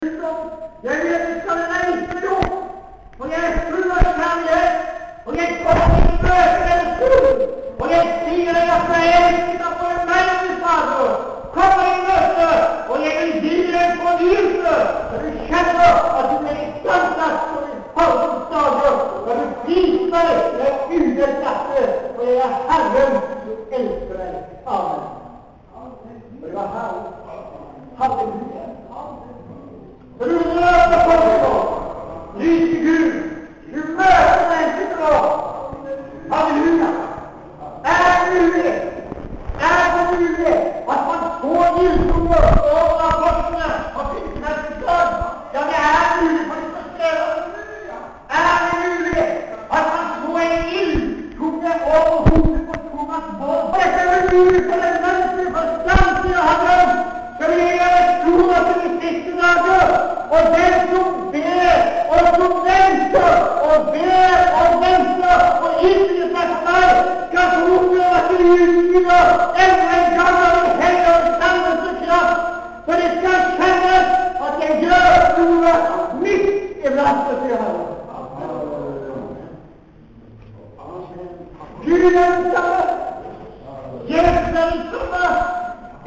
Tyding av tungetale:
Maranata, 2.pinsedag, måndag 1.6.09.